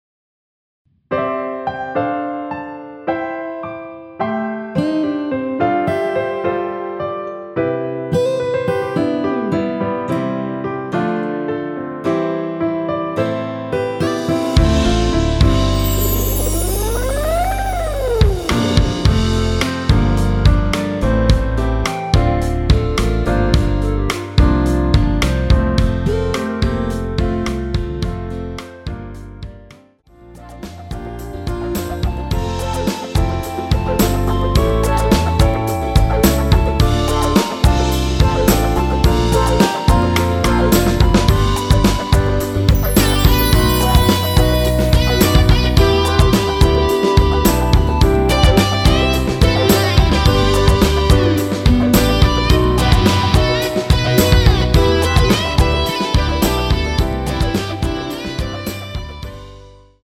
원키에서(-2)내린 멜로디 포함된 MR입니다.
앞부분30초, 뒷부분30초씩 편집해서 올려 드리고 있습니다.